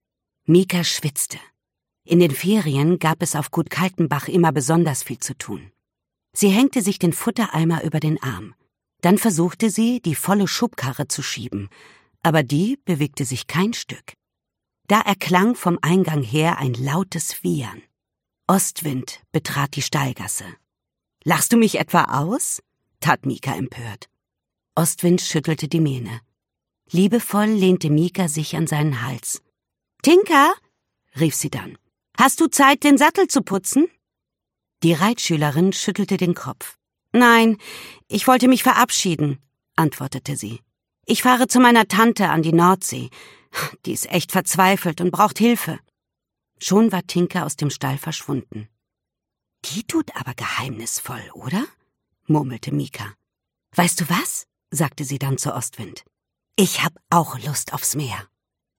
Hörbuch: Ostwind.
Ungekürzte Lesung